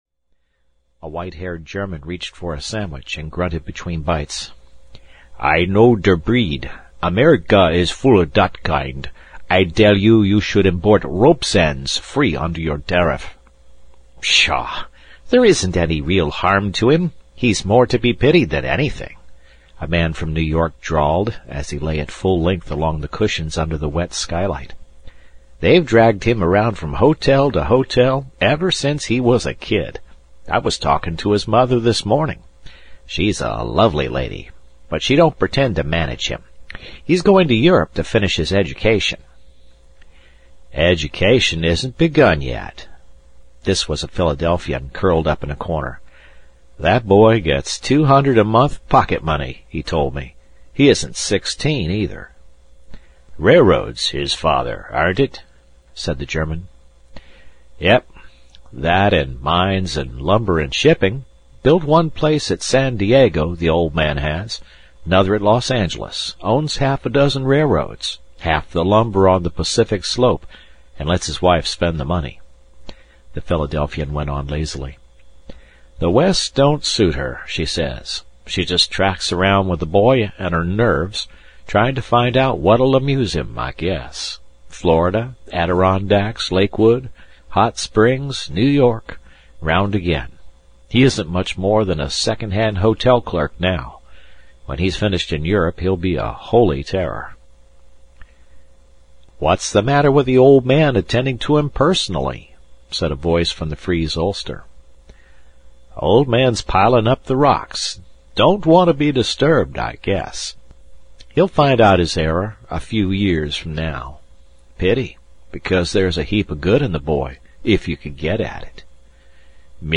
Captain Courageous (EN) audiokniha
Ukázka z knihy